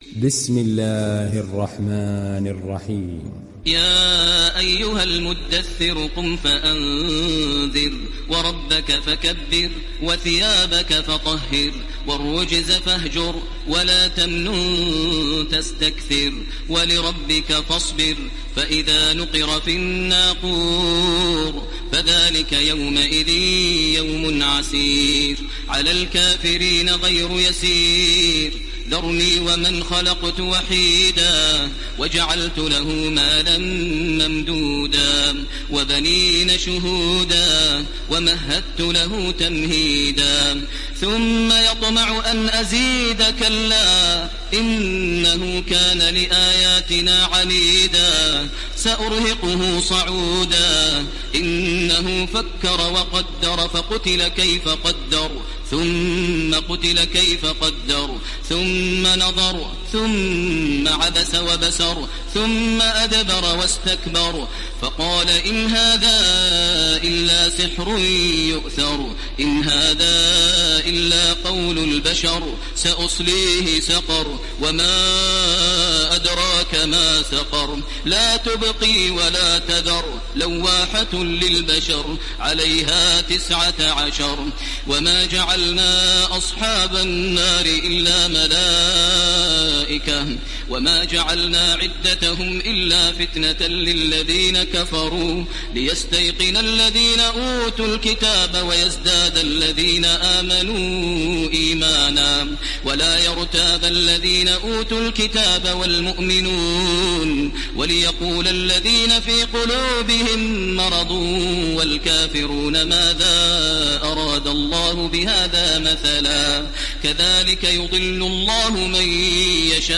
Download Surat Al Muddathir Taraweeh Makkah 1430